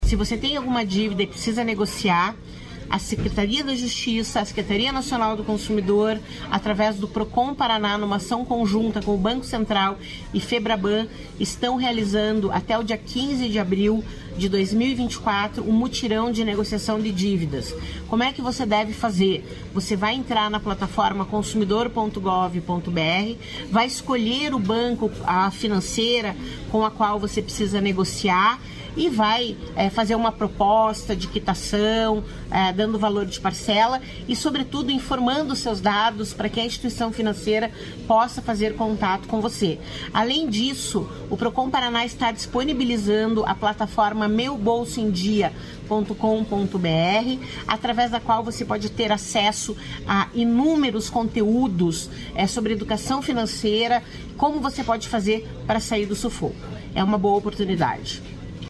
Sonora da coordenadora do Procon-PR, Claudia Silvano, sobre o mutirão online de renegociação de dívidas